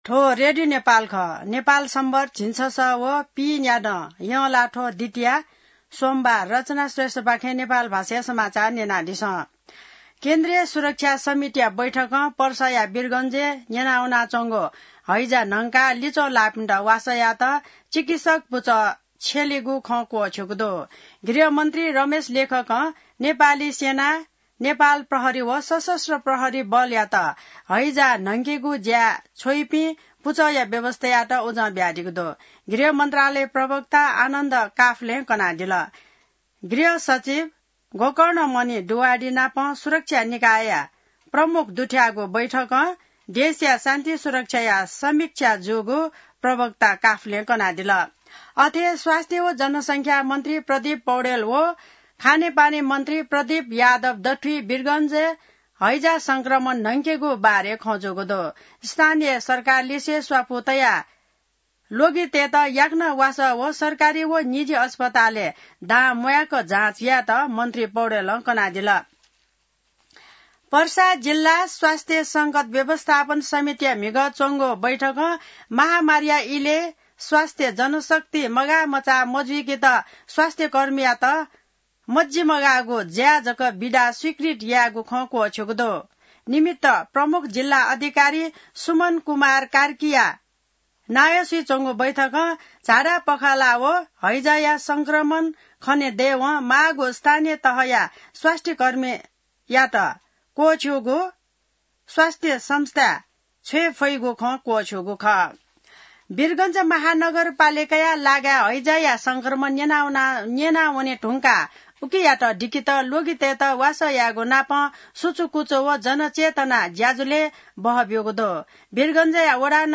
नेपाल भाषामा समाचार : ९ भदौ , २०८२